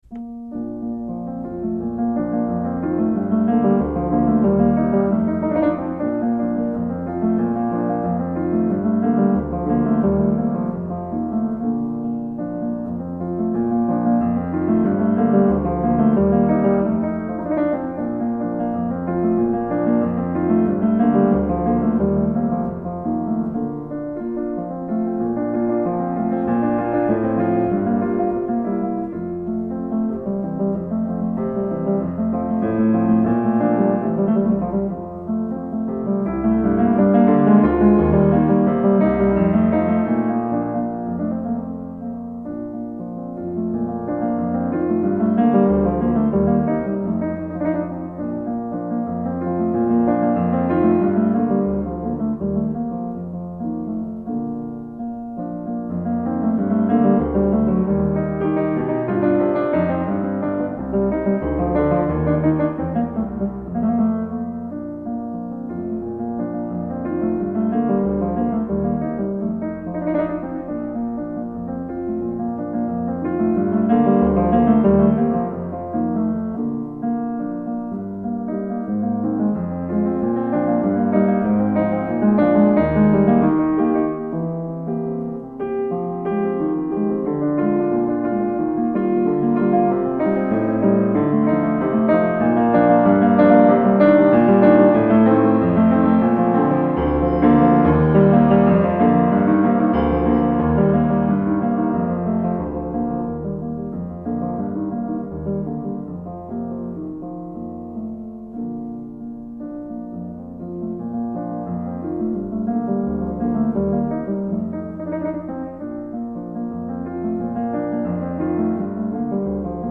Incontro con la giornalista musicale